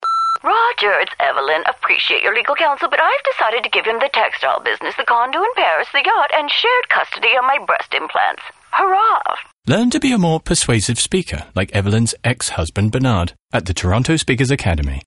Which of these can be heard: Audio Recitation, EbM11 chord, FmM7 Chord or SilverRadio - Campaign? SilverRadio - Campaign